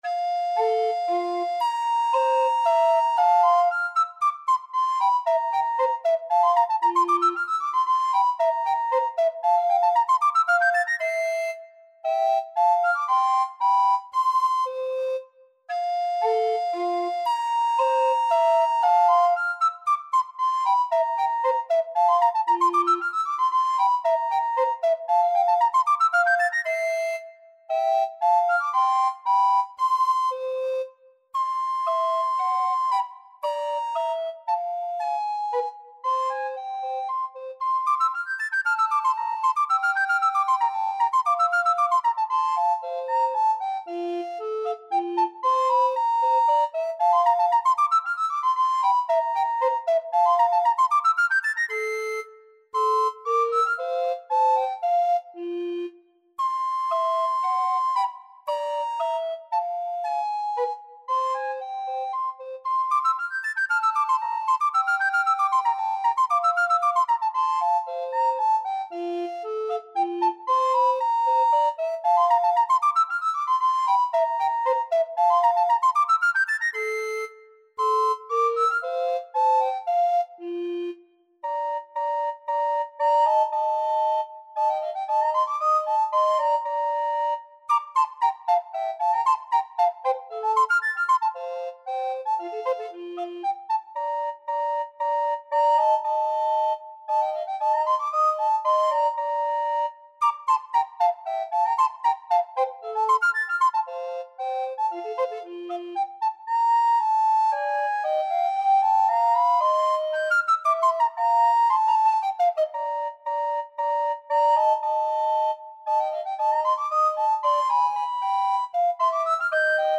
Free Sheet music for Recorder Duet
Soprano RecorderAlto Recorder
F major (Sounding Pitch) (View more F major Music for Recorder Duet )
3/4 (View more 3/4 Music)
Classical (View more Classical Recorder Duet Music)